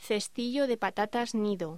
Locución: Cestillo de patatas nido
voz